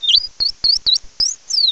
cry_not_shaymin.aif